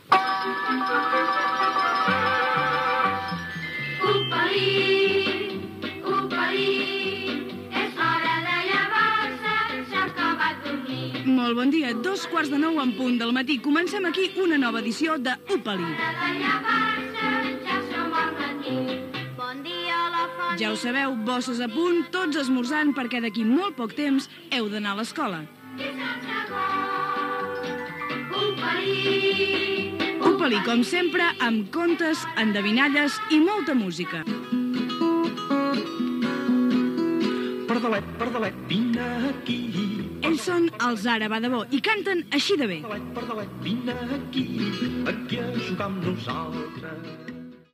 Salutació inicial del programa i tema musical
Infantil-juvenil
FM